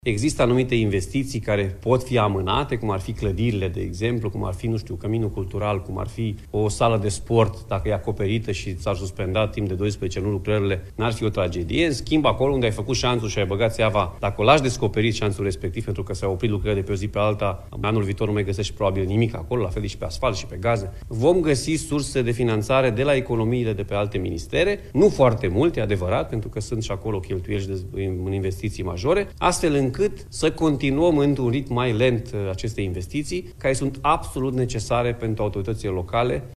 Președintele Uniunii Consiliilor Județene, Alfred Simonis: „Există anumite investiții care pot fi amânate”